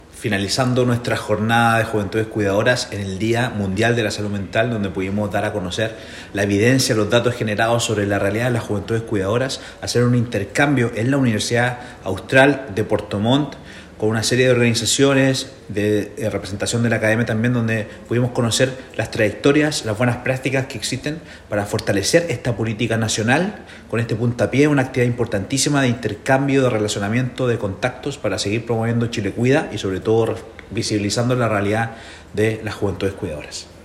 El director nacional del INJUV, Juan Pablo Duhalde, señaló que se dieron a conocer los datos generados sobre la realidad de las juventudes, lo que permitió hacer un intercambio de información en la Universidad Austral de Puerto Montt con una serie de organizaciones y representantes de la academia.